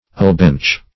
Alebench \Ale"bench`\, n. A bench in or before an alehouse.